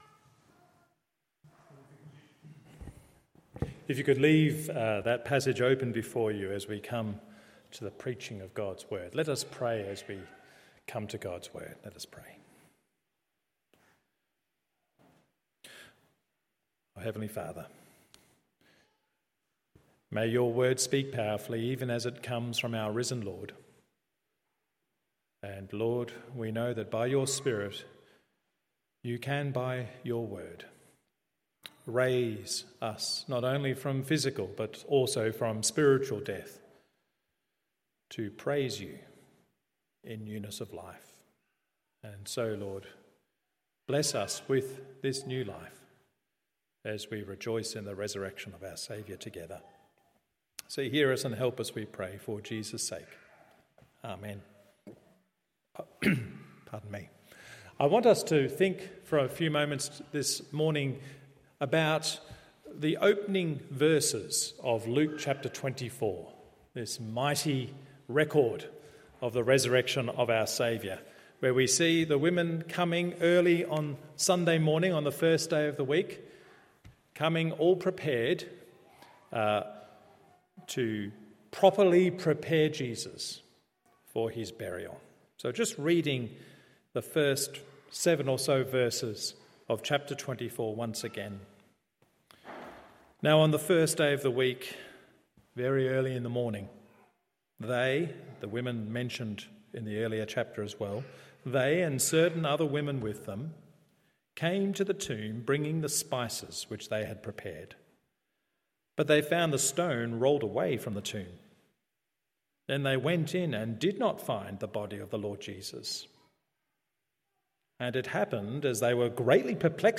Resurrection Sunday Service Luke 24…